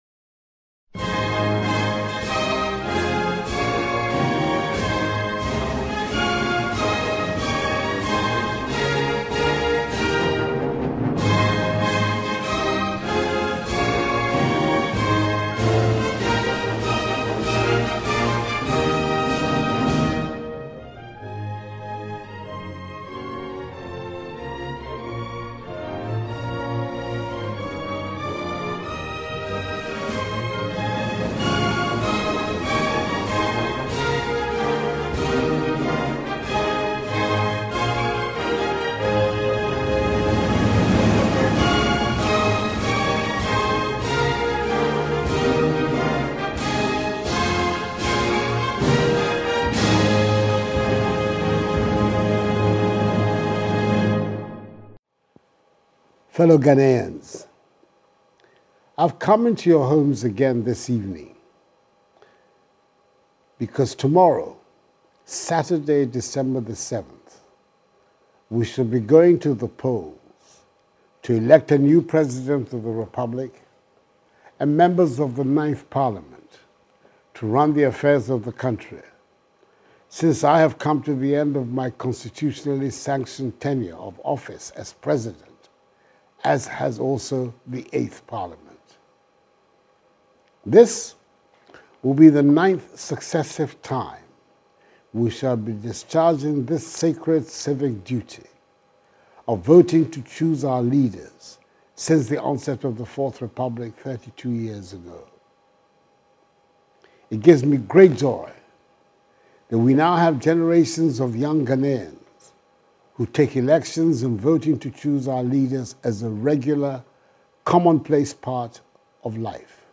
Speaking from Jubilee House in a stirring and reflective address delivered on the eve of Ghana’s 2024 general elections, the President emphasised the significance of the election as a continuation of Ghana’s democratic journey, describing it as a pivotal moment for the nation.
President-Akufo_Addos-Address-Election-Eve.mp3